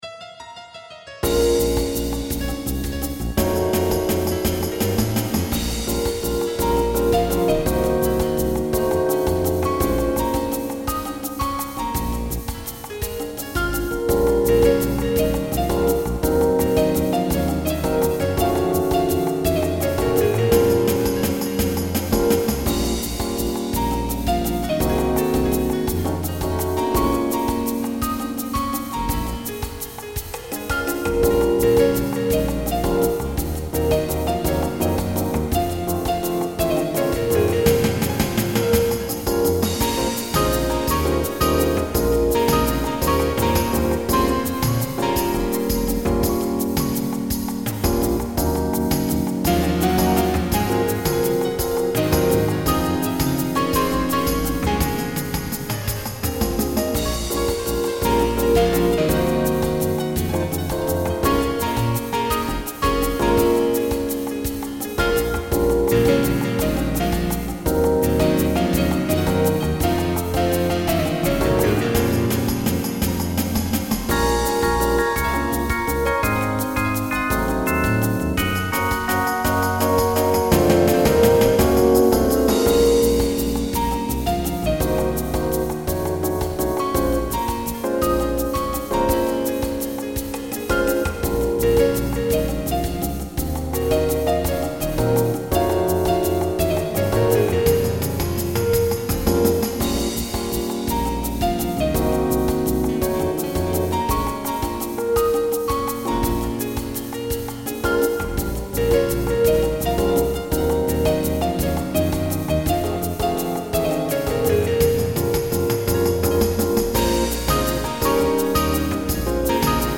Recording from MIDI